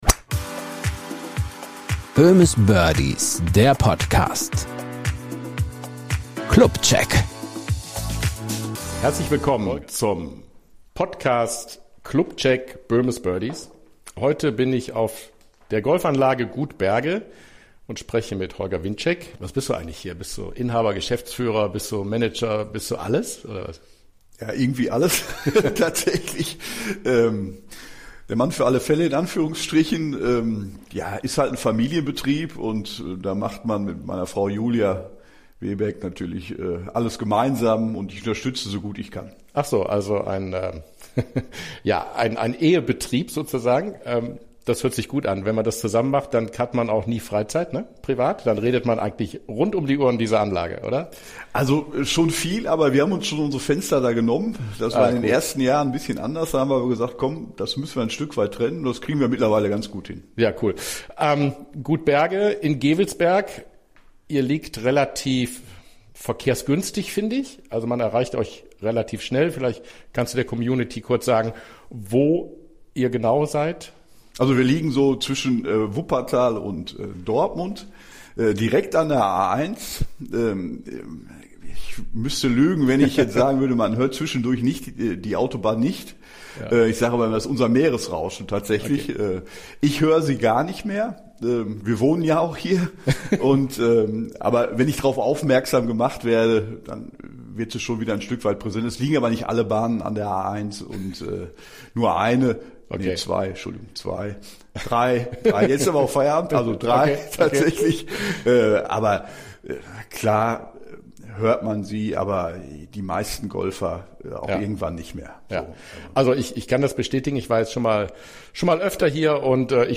Über die Vorzüge der leicht hügeligen Anlage und den besonderen Zusammenhalt der Mitglieder auf Gut Berge unterhalten sich die zwei